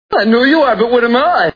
Pee Wee's Play House TV Show Sound Bites